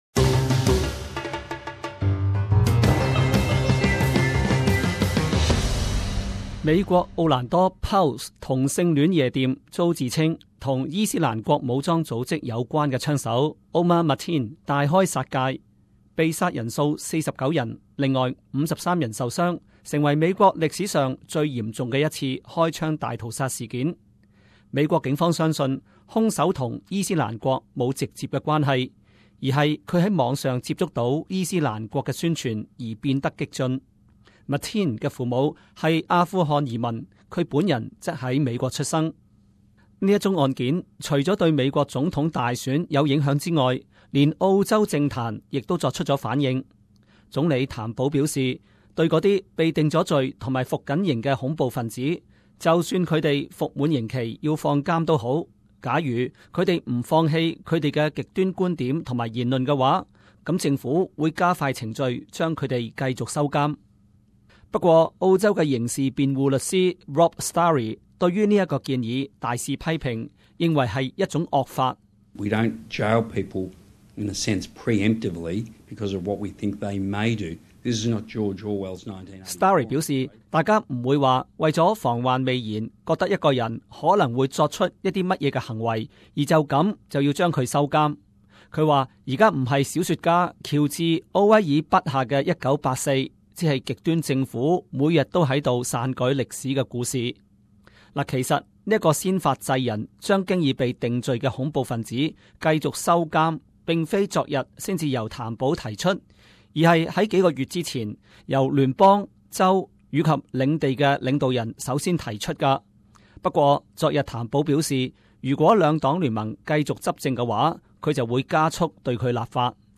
时事报道: 谭保要把恐怖罪犯继续监禁